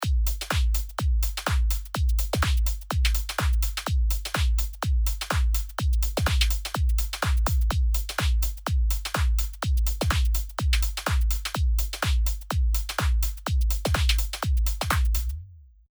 全部かかっている状態なので、一番左のブロック以外はオフにしてみましょう。
かかっているのは“Filter”のみです。